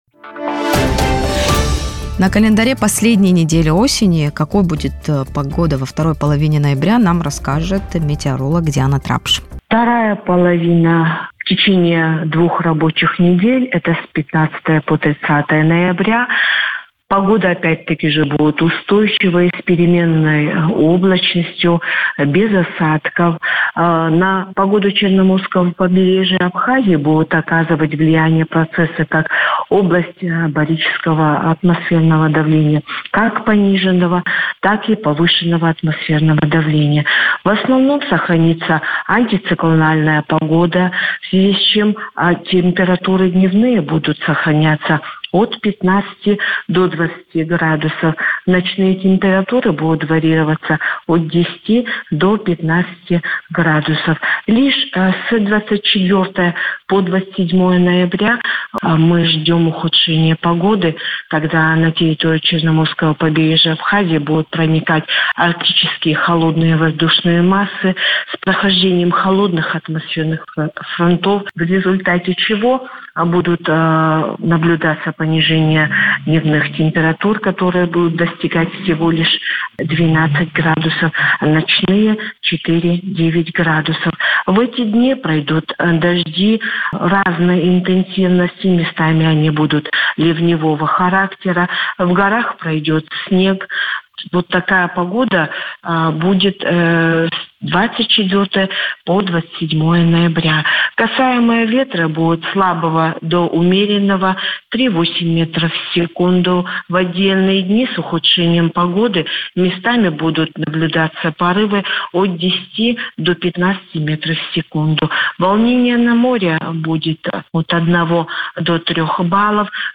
Погода в Абхазии: прогноз метеоролога
метеоролог